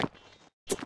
脚步声zth070522.wav
通用动作/01人物/01移动状态/脚步声zth070522.wav
• 声道 單聲道 (1ch)